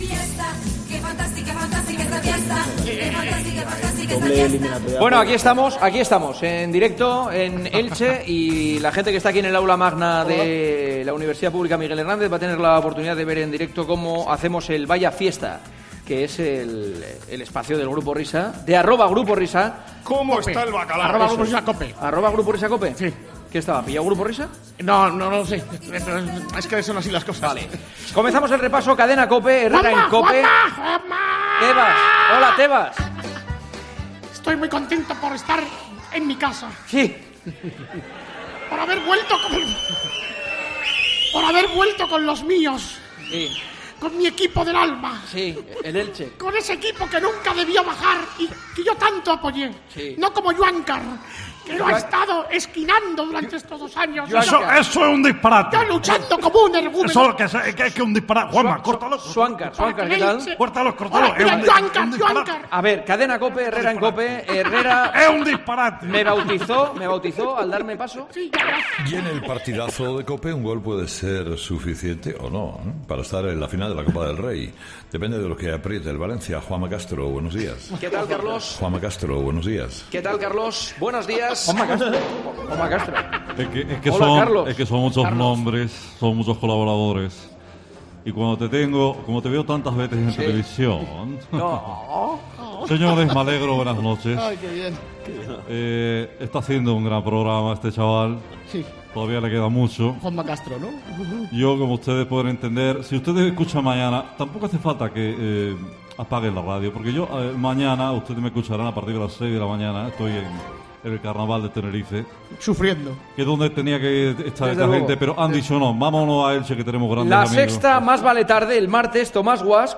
En directo, desde Elche. Diego Godín, en busca de una clínica dental en Valdebebas.